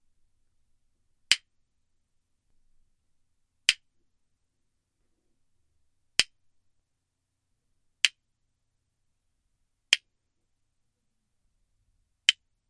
3. A few hand claps.
Below is a short audio segment from my progress in applying the acoustic treatments.
Again, I won’t go into specifics here in the technical side of things, but each click is taken from a test recording done between each step above, and you can hear the sound changing, most importantly with the reflections after the click getting shorter and shorter.